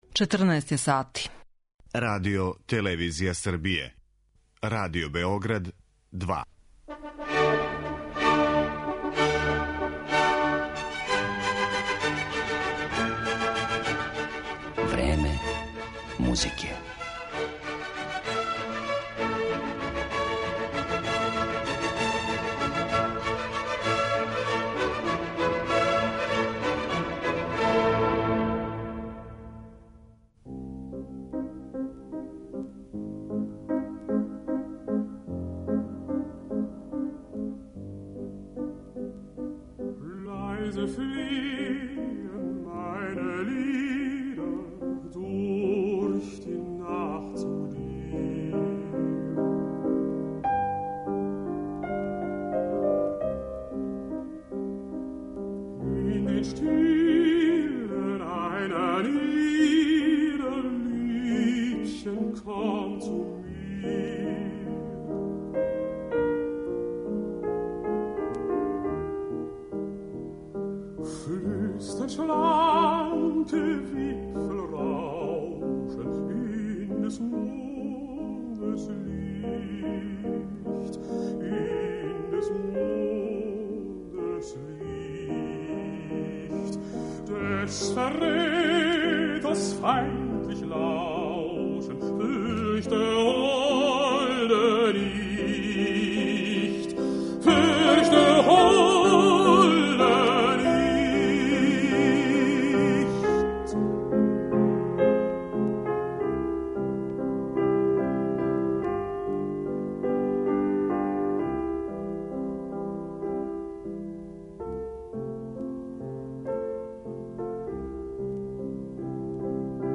Из часописа 'Грамофон' преносимо разговор о Шуберту